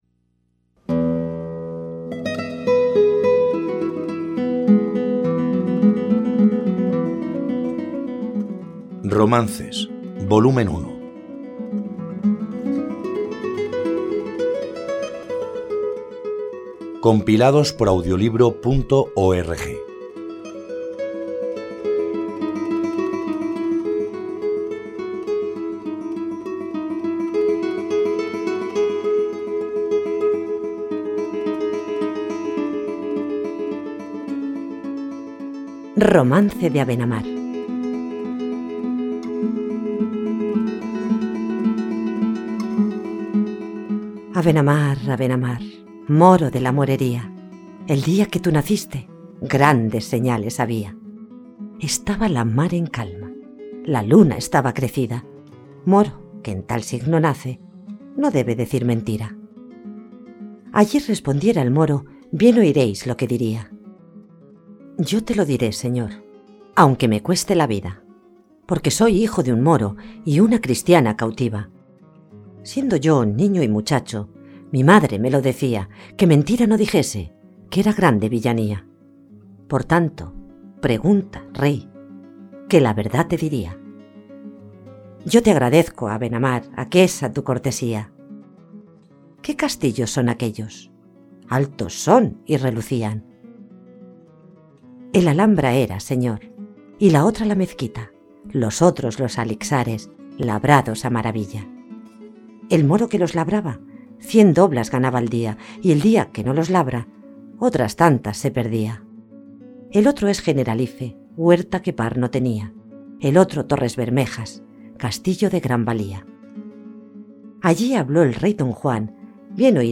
Romances en formato audiolibro